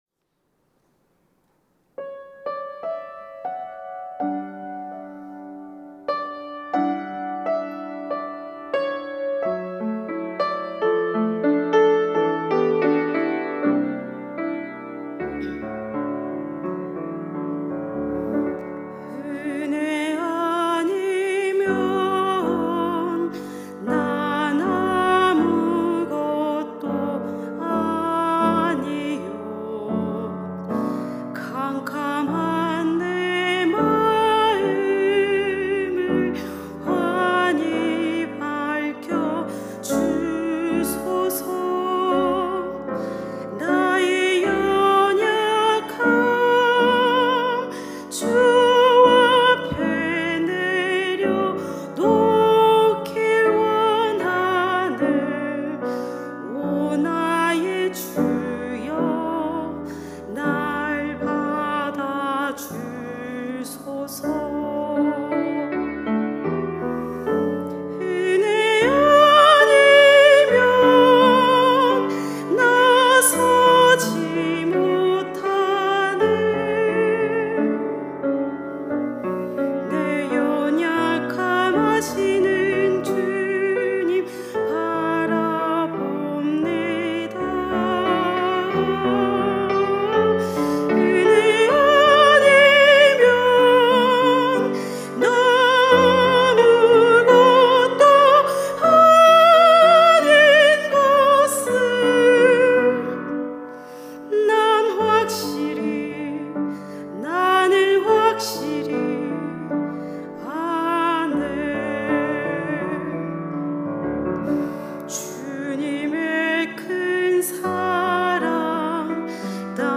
특송과 특주 - 은혜 아니면